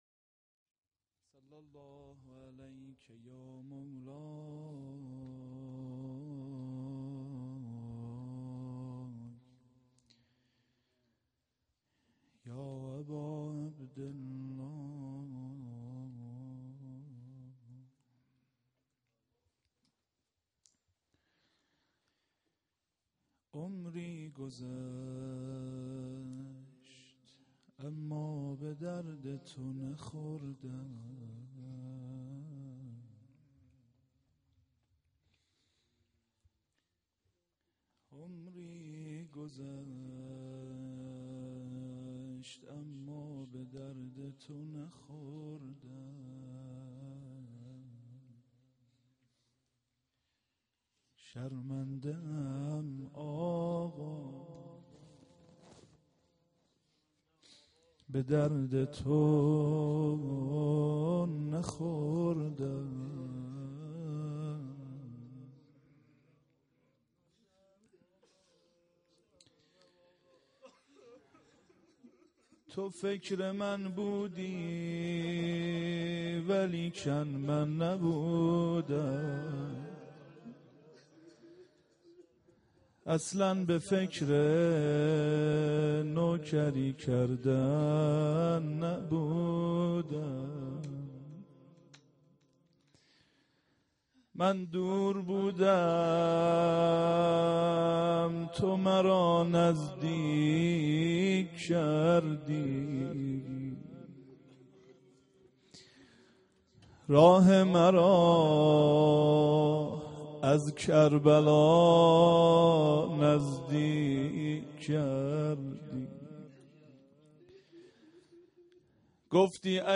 روضه وداع-شب پنجم